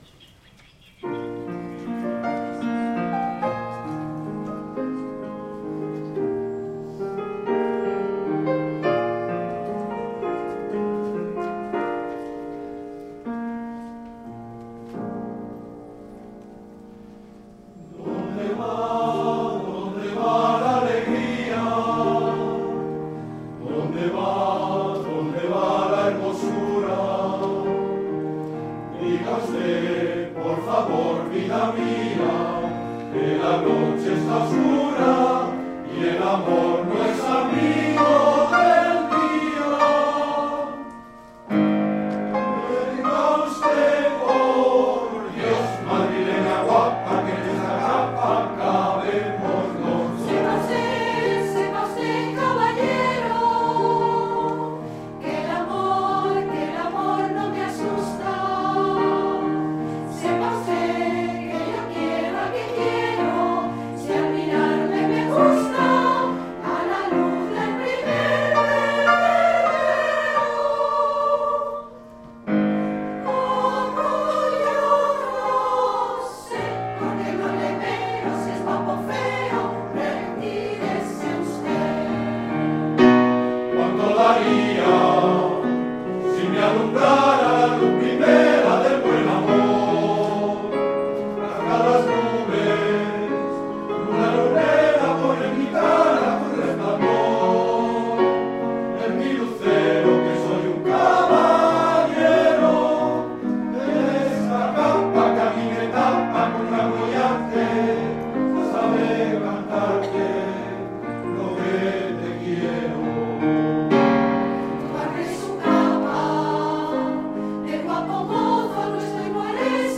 Es una  muestra de  canciones de diferentes estilos que hemos interpretado en los Conciertos (las grabaciones son en directo).